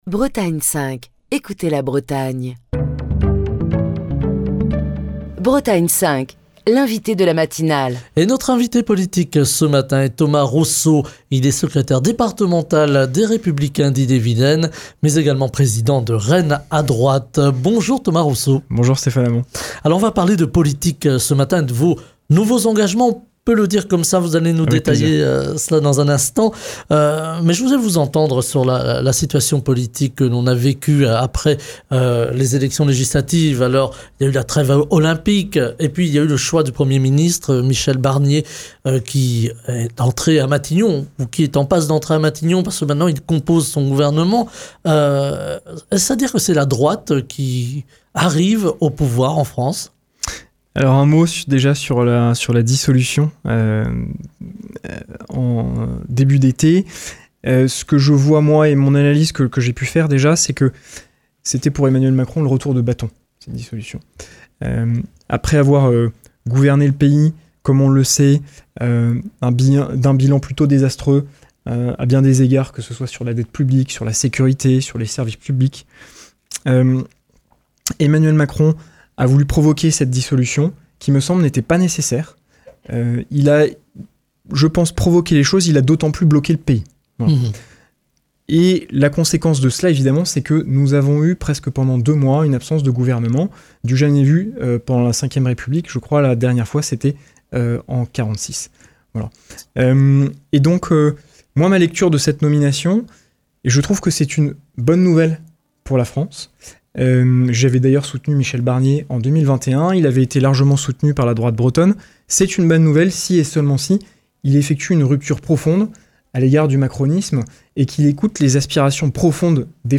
est l'invité politique de la matinale de Bretagne 5. En cette rentrée, il fait le point sur la situation politique après les élections européennes et la dissolution de l'Assemblée nationale.